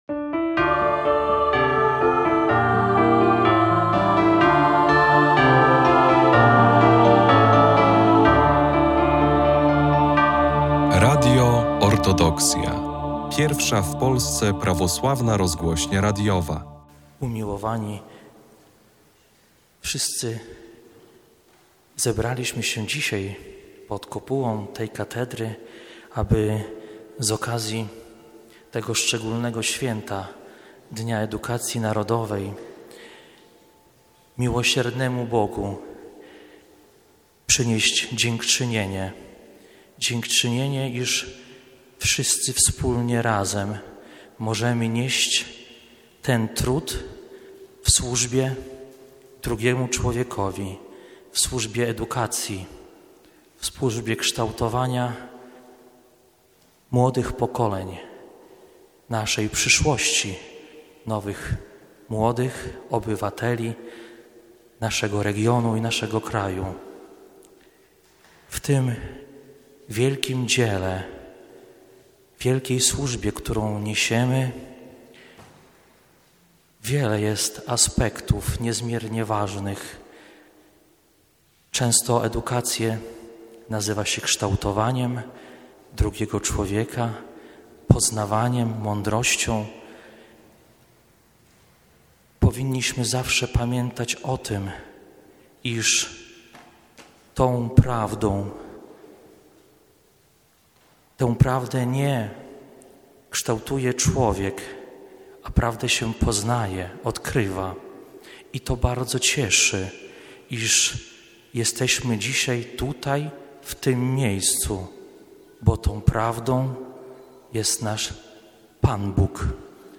15 października JE Najprzewielebniejszy Jakub Arcybiskup Białostocki i Gdański odprawił w Katedrze św. Mikołaja w Białymstoku nabożeństwo dziękczynne – molebien. Z okazji minionego Dnia Edukacji Narodowej wznoszone były modlitwy w intencji dyrektorów szkół, nauczycieli oraz wszystkich pracowników oświaty.